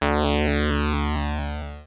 RetroGamesSoundFX / Hum
Hum28.wav